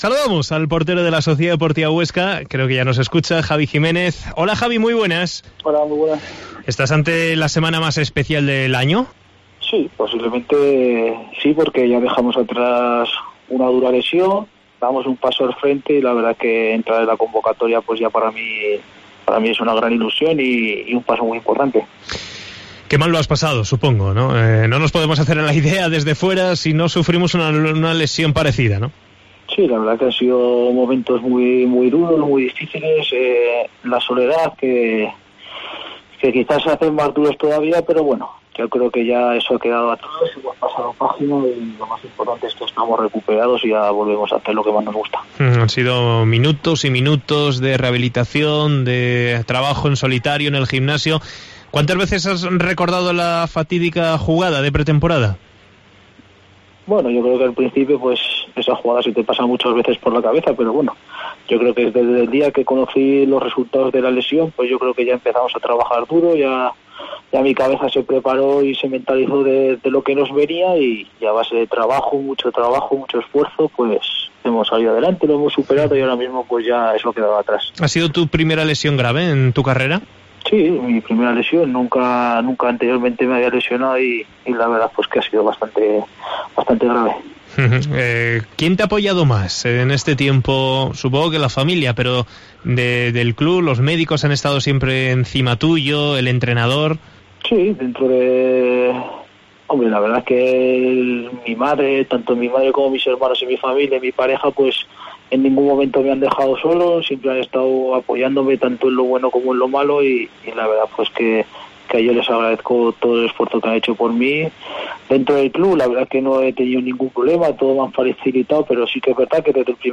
Entrevista
en COPE Huesca